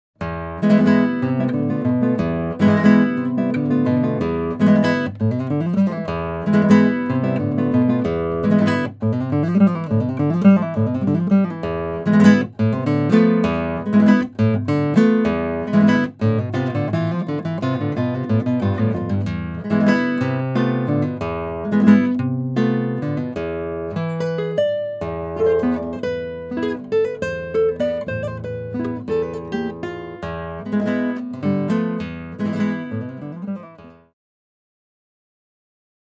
Classical guitar - comparative test of 10 different Oktava, Violet and JZ microphones
recorded simultaneous in Rauschenberg Studio
MONO   Black Knight